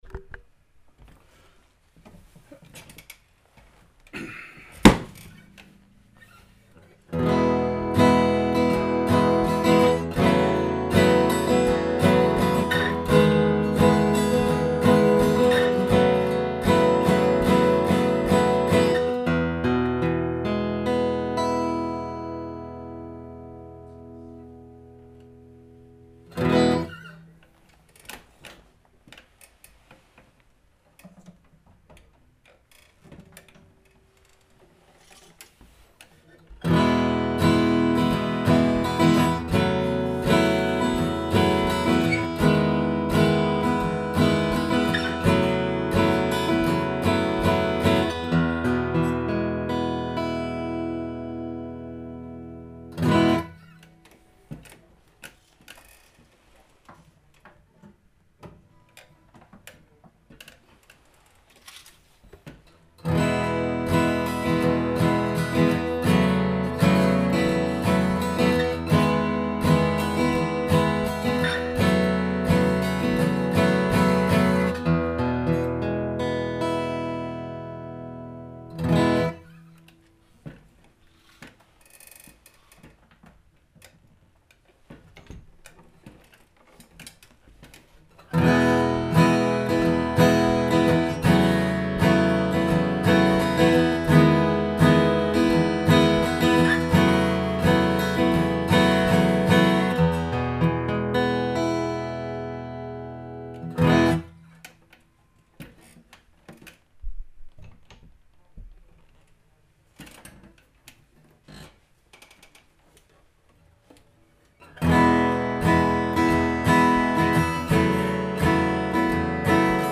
Я собираюсь позволить записям Zoom H4n говорить сами за себя.
Звук на обеих гитарах большой и жирный; сбалансированный и красивый! Отзывчивый к легкому прикосновению и готовый быть окунутым в. Первые две записи очень похожи, мало открытых аккордовых вещей, переходящих с одной гитары на другую из шести разновидностей D28, сидящих в моей скромной гостиной.
E Материал на шести гитарах D28